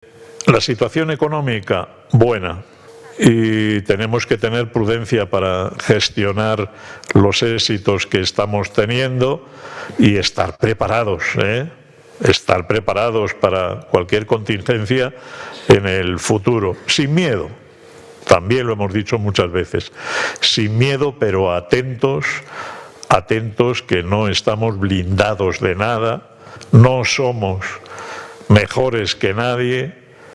El CCG fue clausurado por el presidente del Grupo Social ONCE, Miguel Carballeda, quien durante su intervención hizo alusión, entre otros temas, a la “buena situación económica” actual del Grupo, aunque apeló a la “prudencia para saber gestiona los éxitos que estamos teniendo y